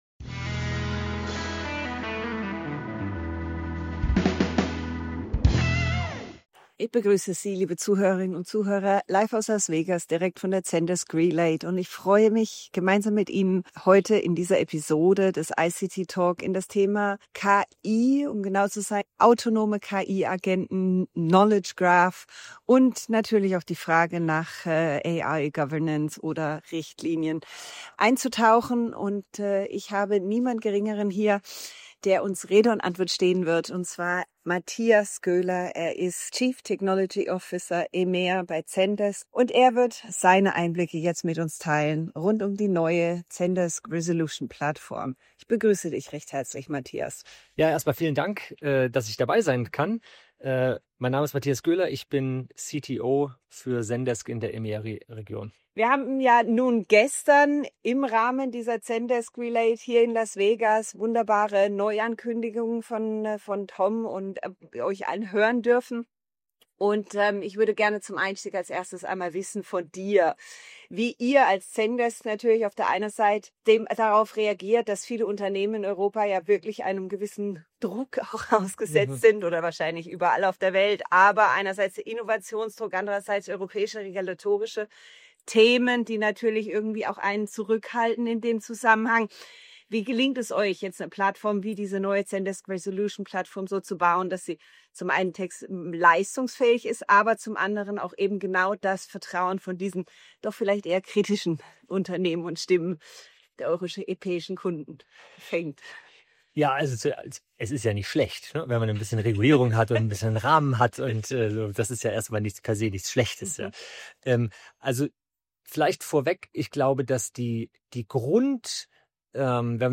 Direkt von der Zendesk Relate in Las Vegas gibt er Einblicke in autonome KI-Agenten, Co-Piloten, den Einsatz von Knowledge Graphs – und warum Transparenz und Vertrauen zentrale Erfolgsfaktoren in regulierten Märkten wie Europa sind. Erfahren Sie, wie die neue Generation KI-Agenten funktioniert, was sie wirklich leisten können – und wie Unternehmen heute eine „Resolution Revolution“ starten, die mehr ist als ein Buzzword.